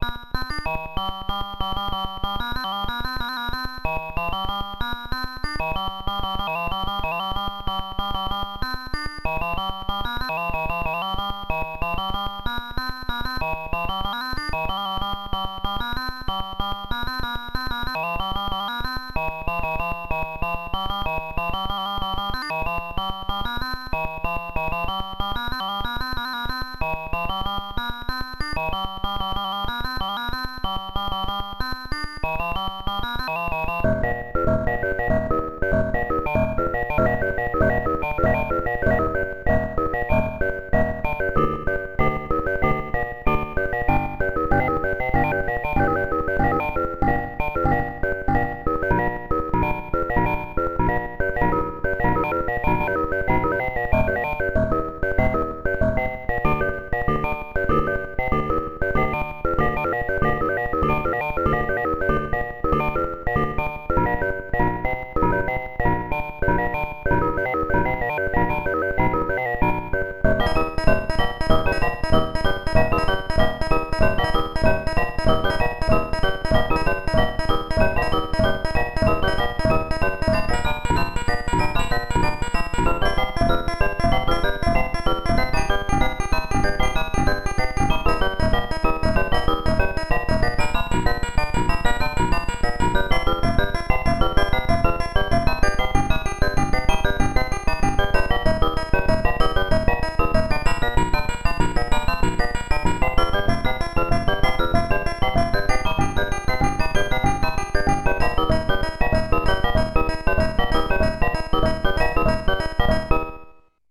It can do microtonal synthesis using 8 oscillators, each having 32 8-bit waveforms to choose from, and 4 very rough amplitude envelope generators.
Pitches can either be defined using ratios or cents for microtonal synthesis and the sequencing algorithms are completely independent of each other for multitemporal sequencing.
knyst_synthesizer_03.mp3